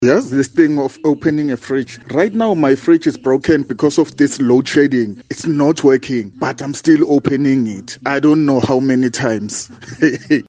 Here’s what Kaya Drive listeners had to share about their refrigerator habits: